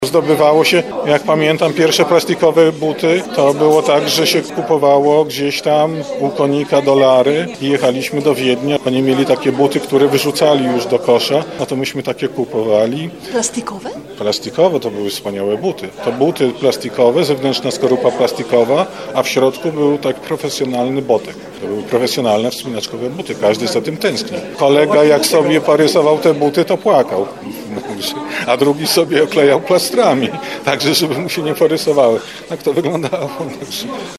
Na urodzinowym spotkaniu wspominał jak zdobywało się wtedy plastikowe buty do wspinaczki.